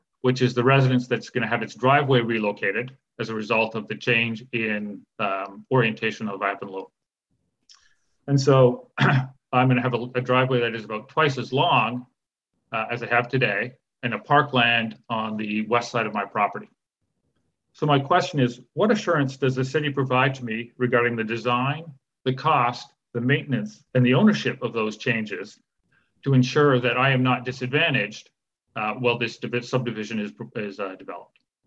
At Tuesday’s Planning Advisory Committee meeting a public meeting was held regarding the proposed Village of Avonlea subdivision.
Following a presentation from the applicants, members of the public were given an opportunity to have their say.